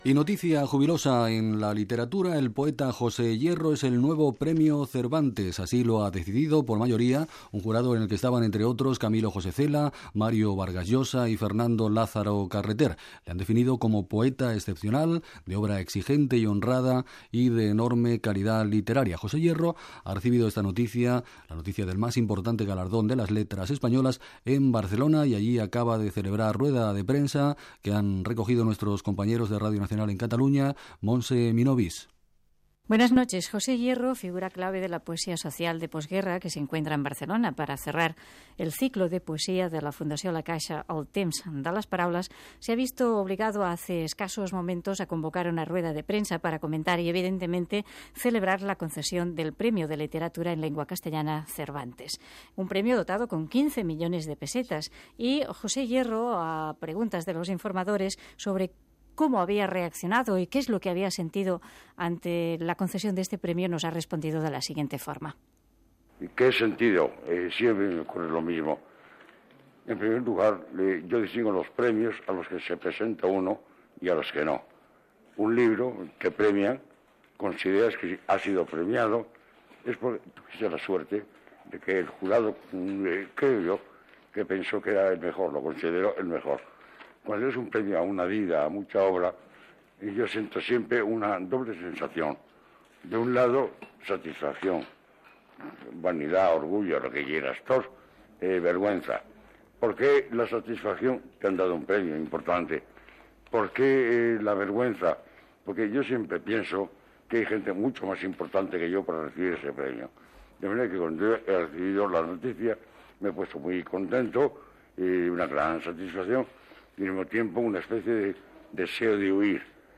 A l'escriptor José Hierro se li ha concedit el Premio Cervantes. Reacció de l'escriptor des de Barcelona
Informatiu